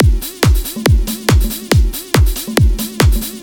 beat beats drumkit fast Gabber hardcore House Jungle sound effect free sound royalty free Music